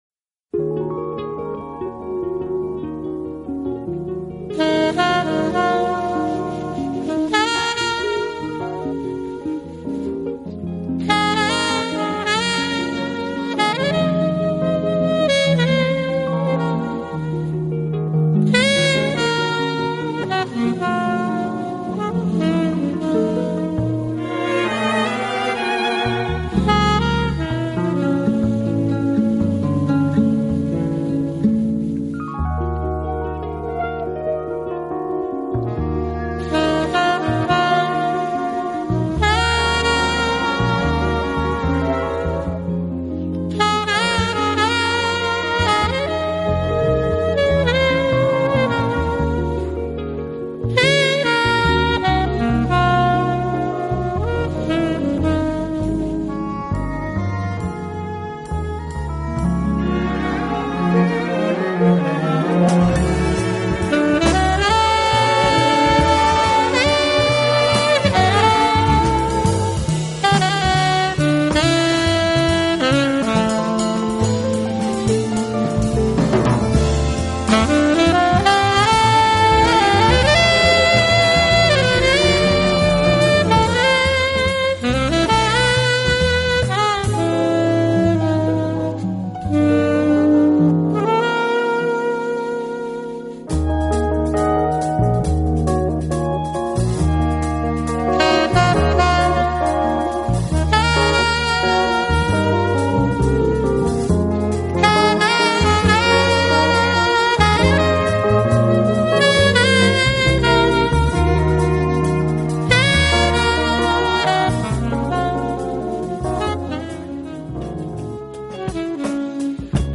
他的演奏风格包容了Jazz、